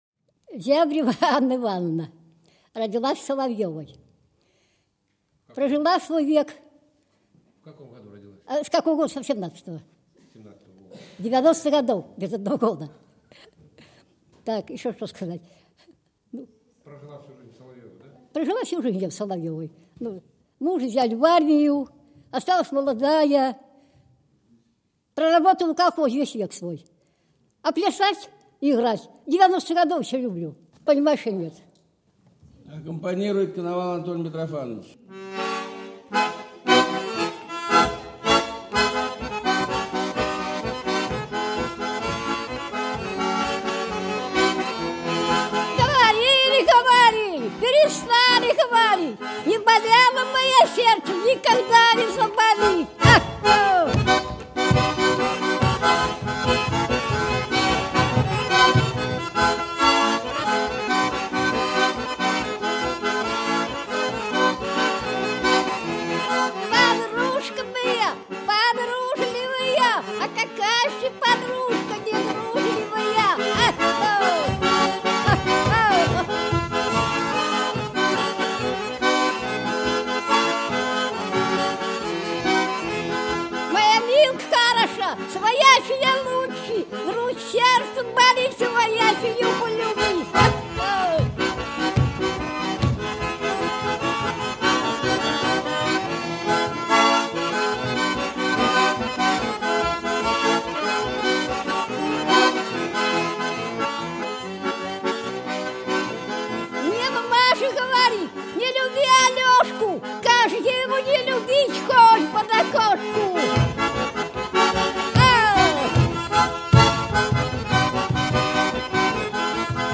Деревенская гармонь. Экспедиционные записи 2006 - 2011 г.г. - Русская гармонь
Матаня, частушки.
елецкая рояльная гармонь Матаня